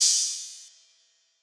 pbs - low [ OpHat ].wav